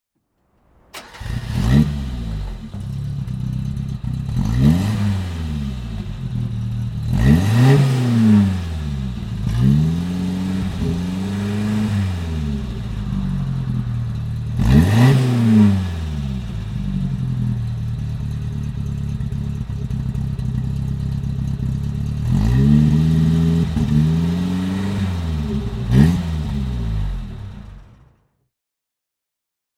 Suzuki LJ 80 (1981) - Starten und Leerlauf